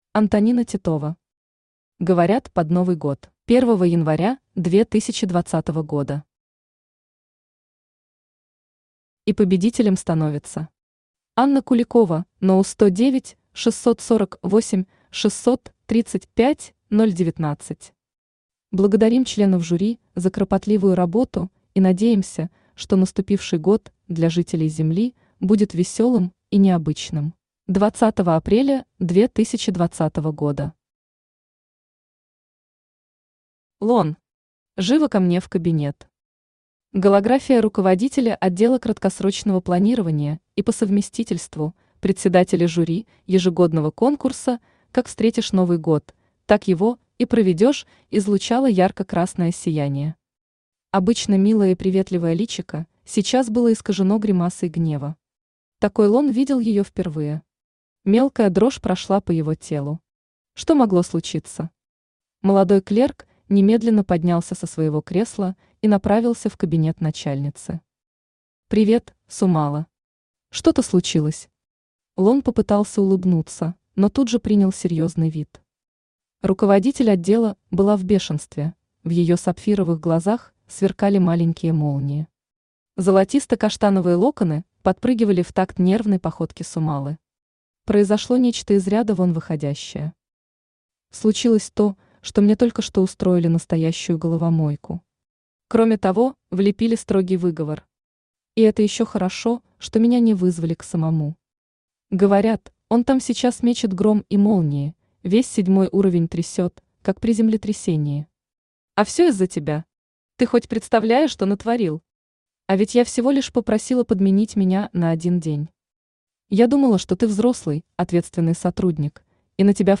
Аудиокнига Говорят, под Новый год…
Автор Антонина Титова Читает аудиокнигу Авточтец ЛитРес.